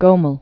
(gōməl, -myĕl)